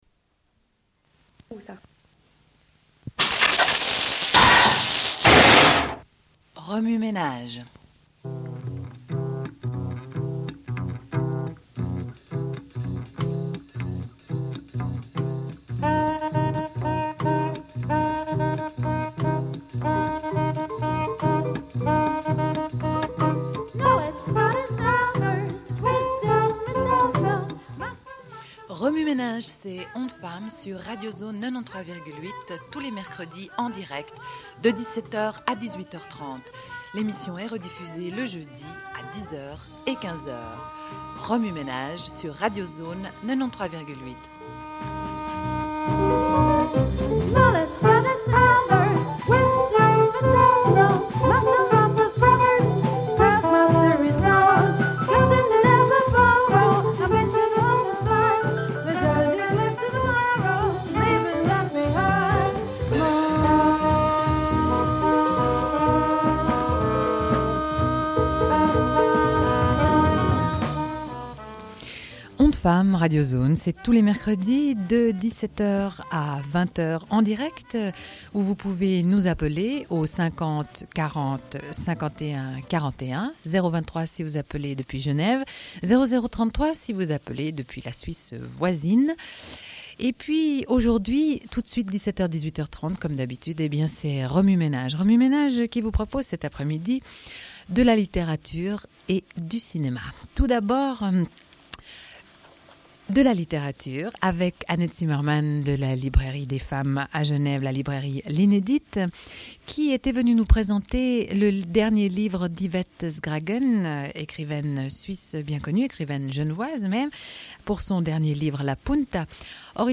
Une cassette audio, face A30:59